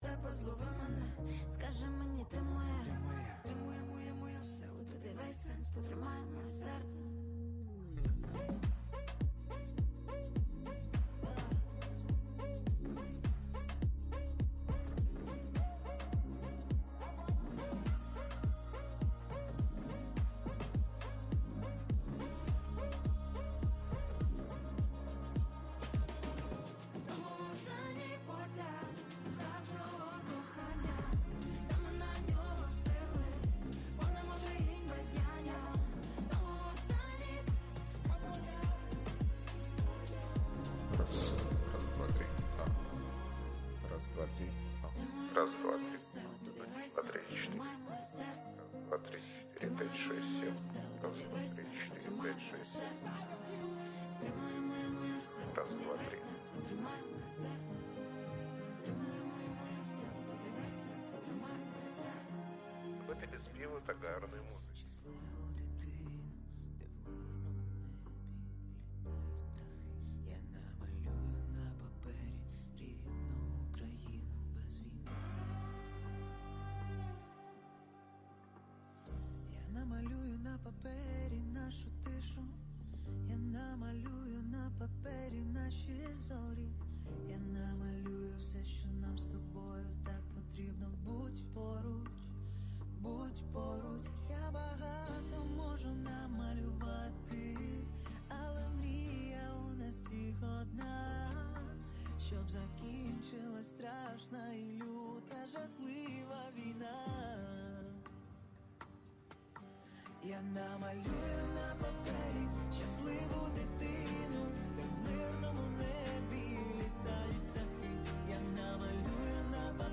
Одесса караоке-бар PRINCE Аркадия on 16-May-23-19:51:28
Караоке вечори в Одесі